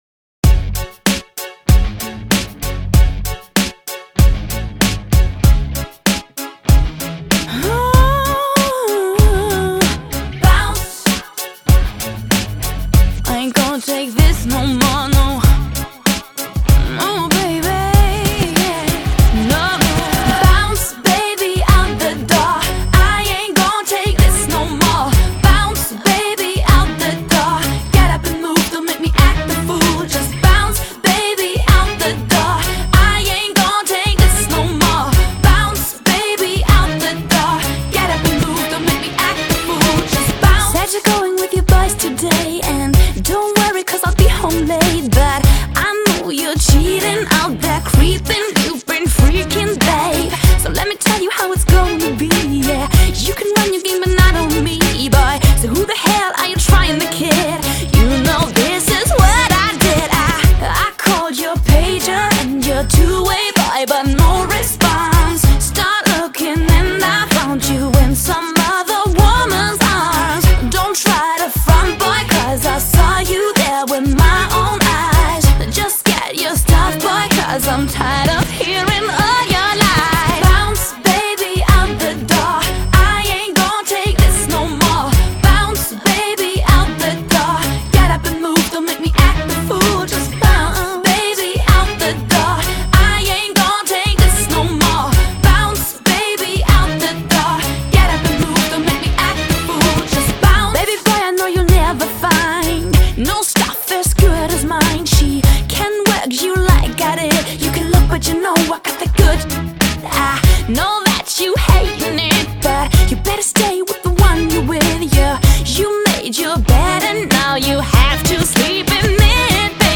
以甜美、带劲的节奏蓝调歌唱实力开始征服欧洲乐坛